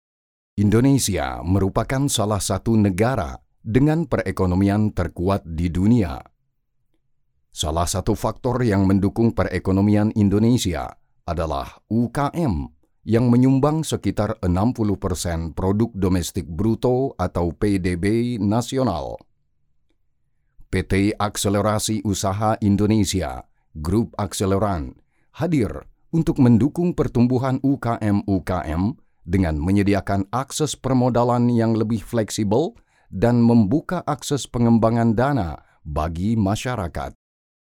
Commercial, Deep, Natural, Versatile, Corporate
Corporate
Many people say that his voice is distinctive, strongly masculine, and pleasant to listen to.